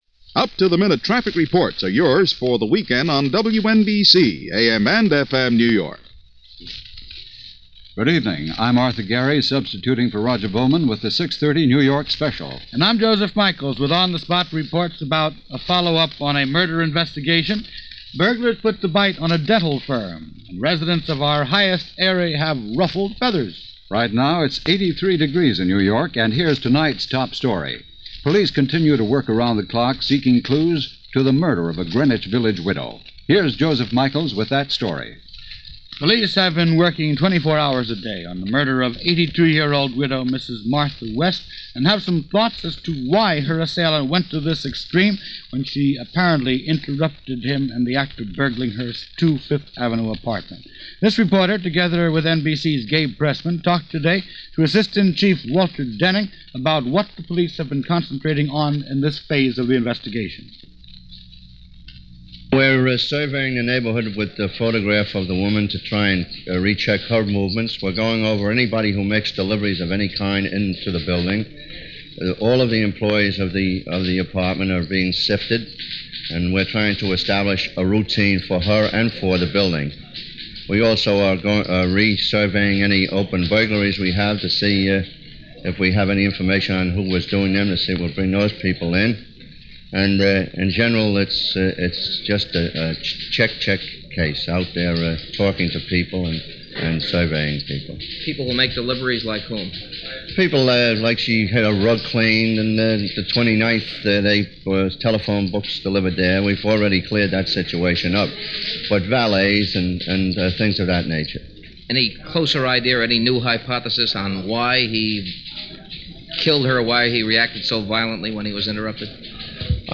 The notion that human beings don’t change all that much over the years is evident in this local newscast, from WNBC-AM&FM in New York City for the evening of July 2, 1964.